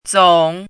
zǒng
zǒng.mp3